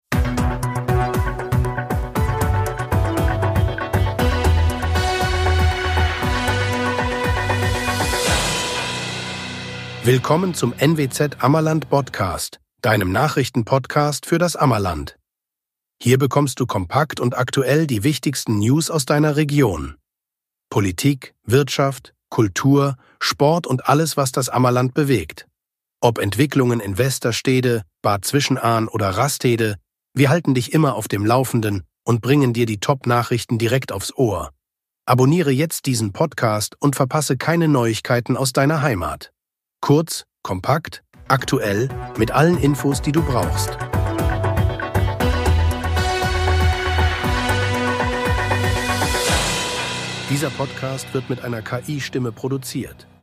Willkommen zum NWZ Ammerland Botcast – deinem Nachrichten-Podcast
Dieser Podcast wird mit einer KI-Stimme